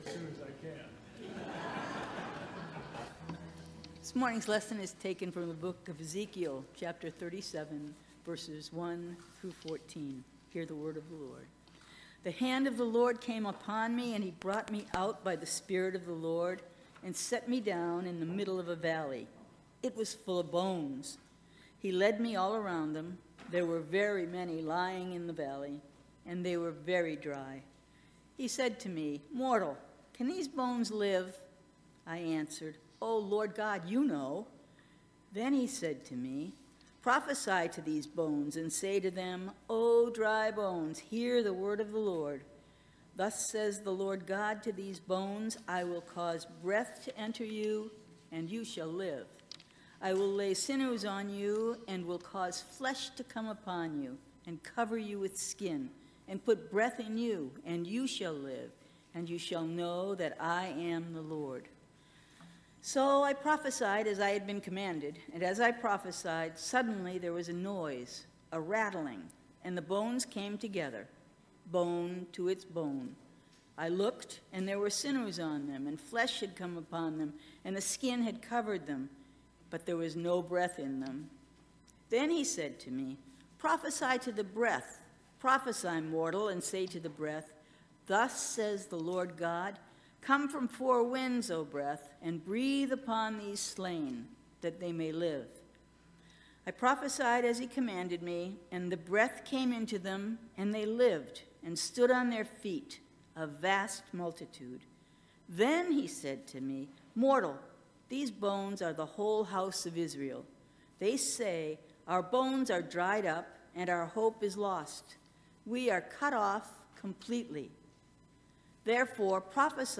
Scripture-Reading-and-Sermon-July-2-2023.mp3